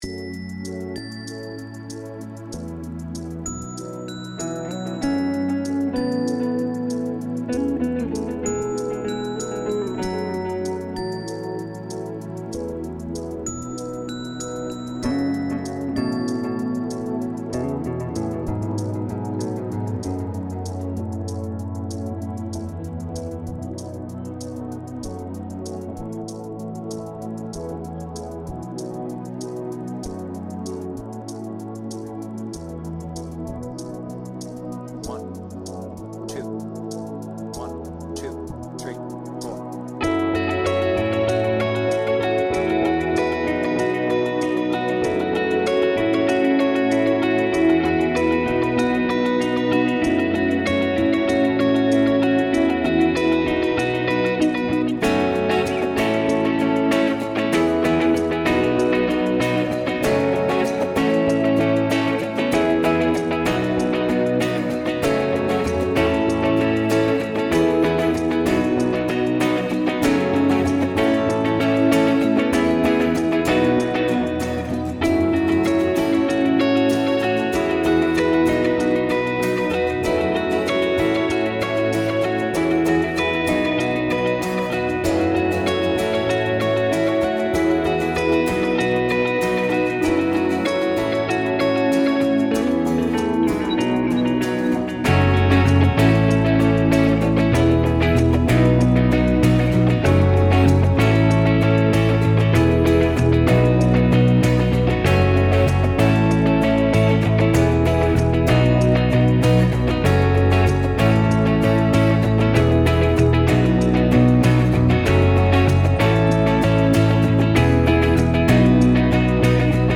BPM : 146
With Vocals